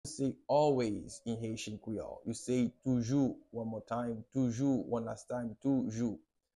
How to say “Always” in Haitian Creole – “Toujou” pronunciation by a native Haitian Teacher
“Toujou” Pronunciation in Haitian Creole by a native Haitian can be heard in the audio here or in the video below: